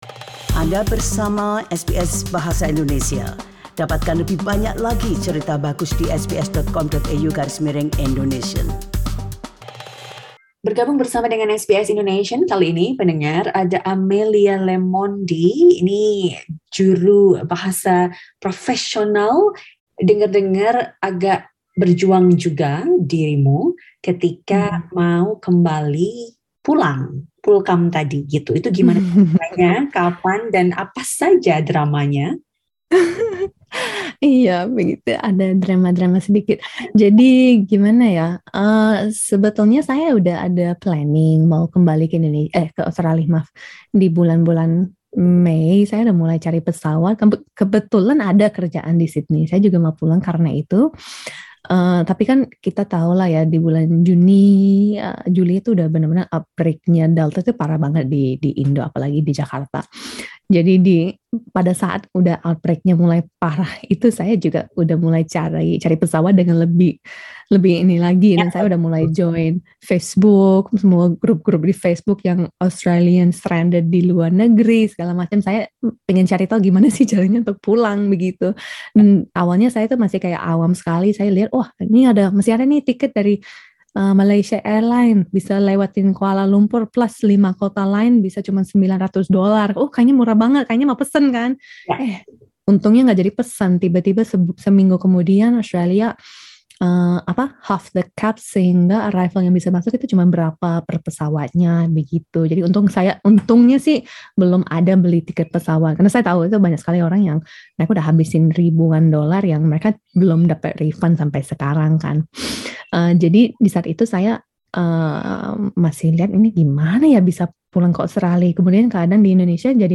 Listen to the full interview on the SBS Indonesian's podcast.